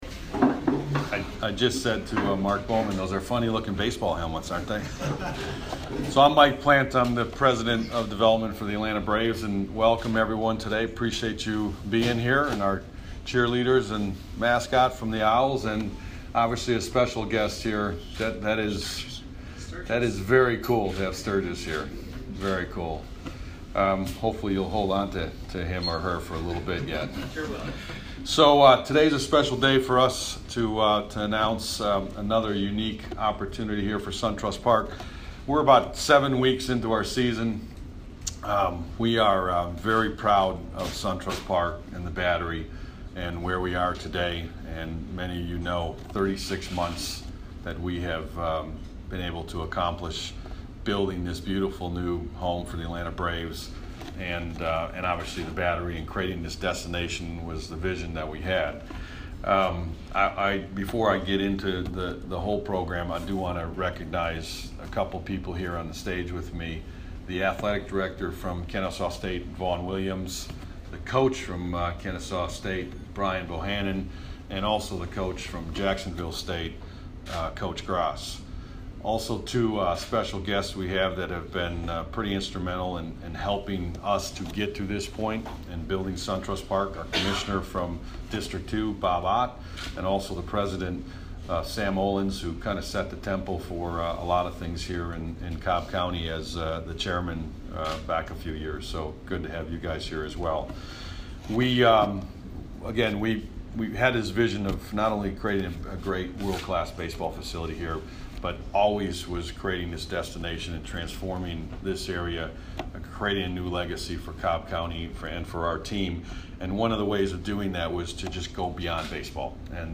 PRESS CONFERENCE (AUDIO)